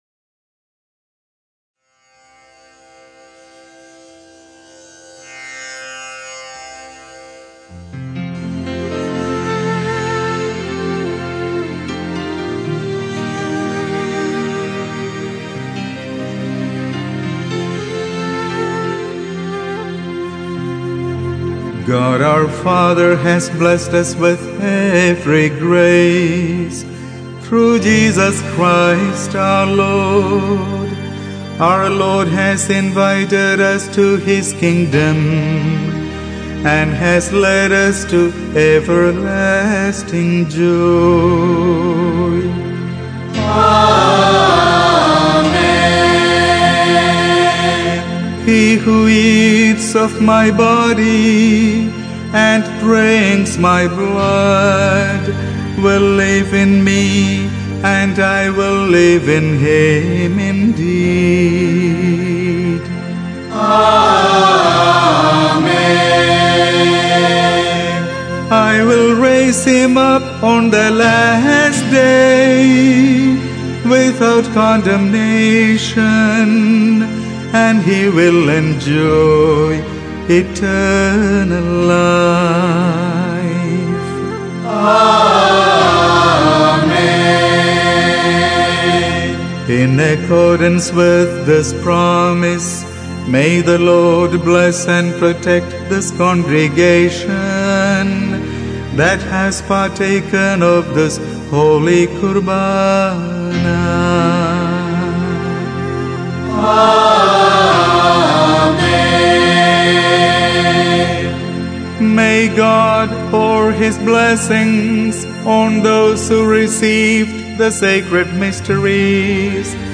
Bamboo flute
Category Liturgical
Performance space Church